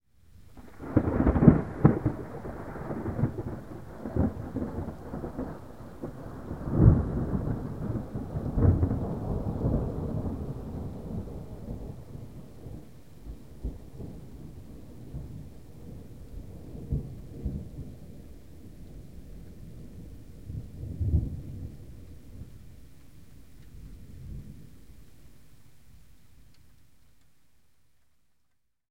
描述：Sound of tornado sirens wailing as a powerful thunderstorm packing a tornado, heavy rain and hail rolled across Lincoln, Nebraska.
标签： storm rain weather severe lightning hail thunderstorm tornado nature fieldrecording sirens
声道立体声